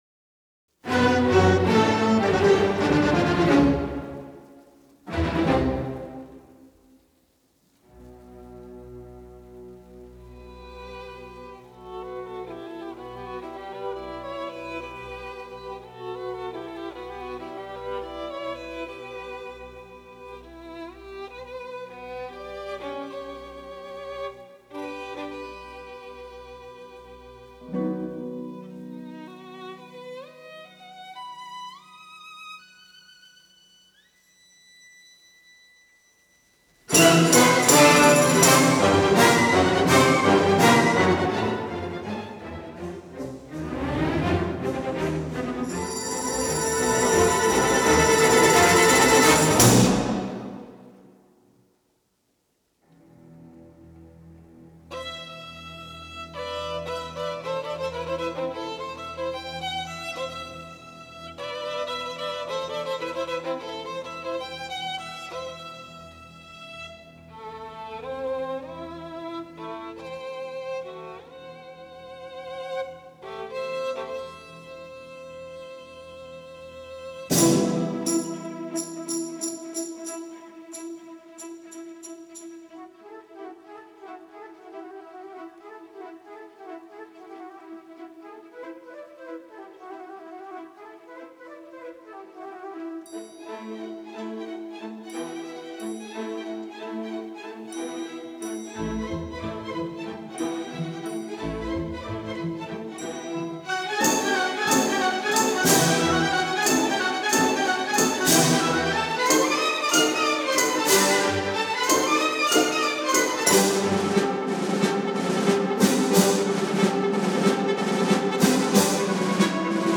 Recorded: 20-22 October 1962, Wembley Town Hall, London.